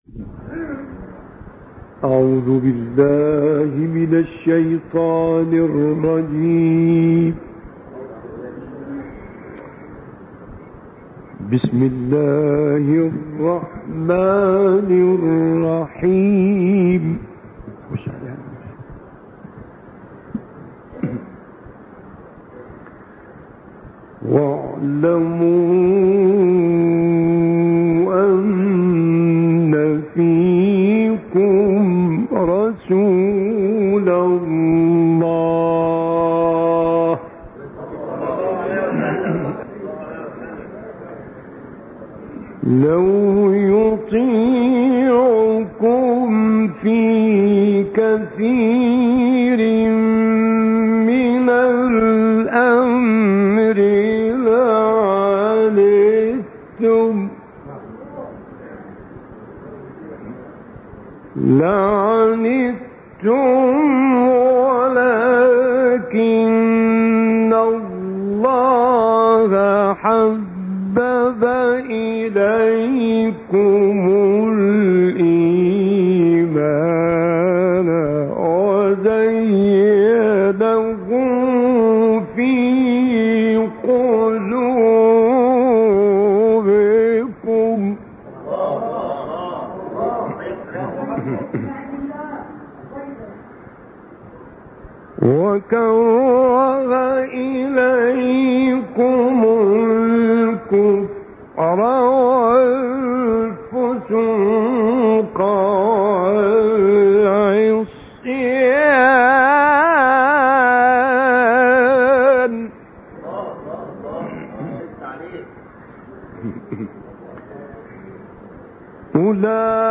تلاوت آیاتی از سوره مبارکه حجرات با صدای استاد مصطفی غلوش
تهران- الکوثر: راغب مصطفی غلوش از قاریان نامدار جهان اسلام است و در اینجا تلاوتی کمتر شنیده شده از وی شامل آیاتی از سوره مبارکه حجرات را می شنوید.